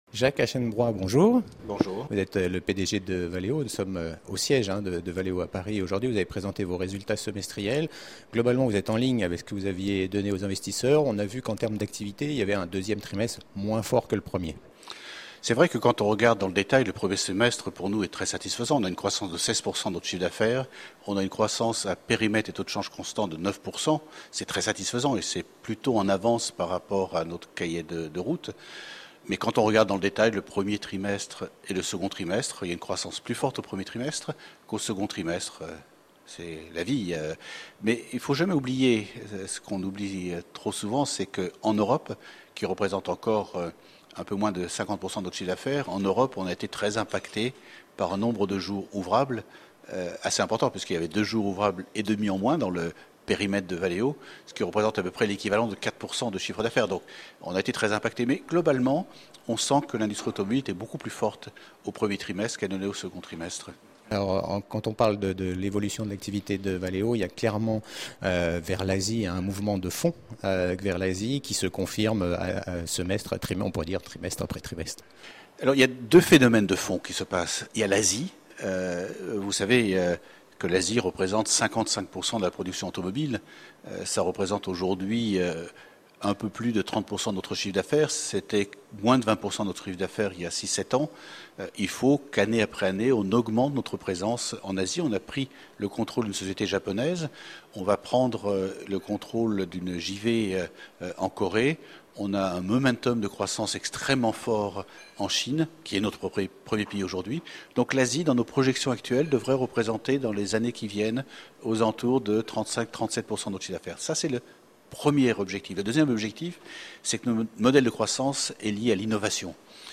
Les tendances et les perspectives pour le second semestre sont également évoquées avec mon invité, Jacques Aschenbroich Pdg Valeo.